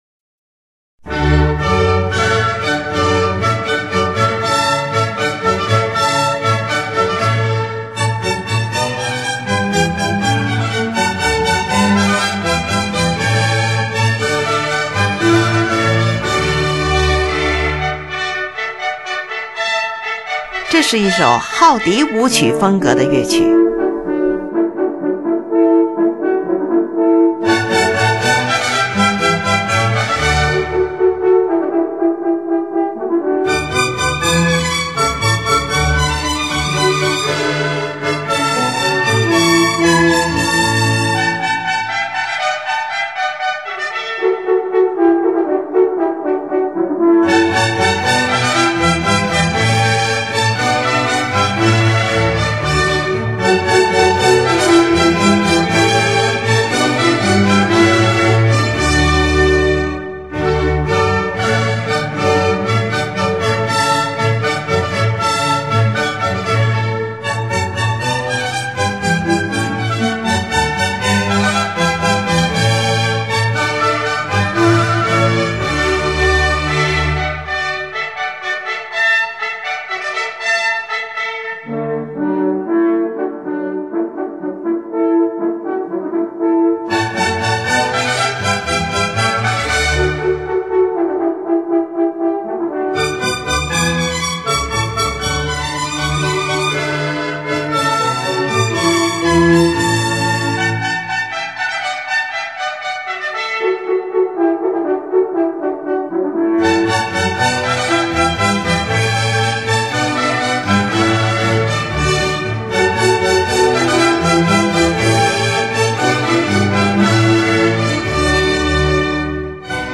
你们可别误会了，以为它就一定是号与笛子吹奏出来的，它其实是16世纪初的一种三拍子的舞蹈音乐。
乐器使用了小提琴、低音提琴、日耳曼横笛、法兰西横笛、双簧管、圆号、小号等